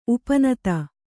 ♪ upa nata